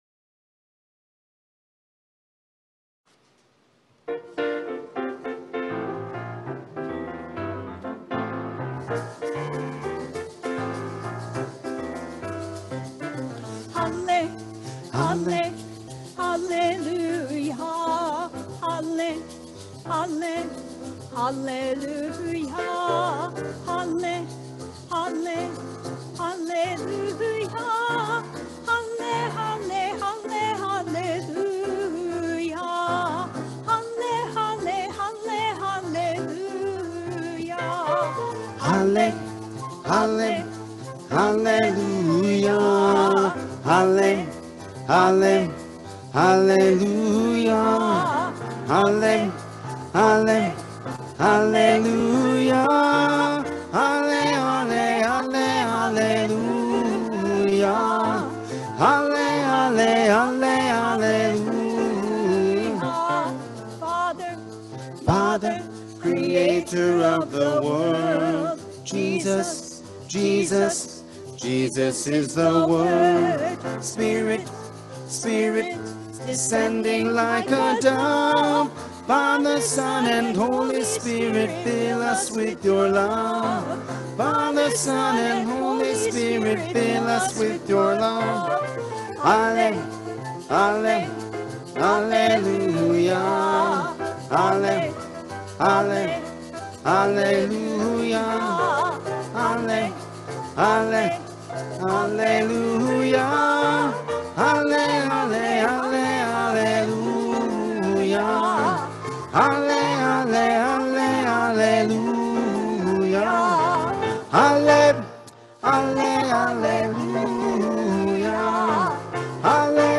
Gospel and homily only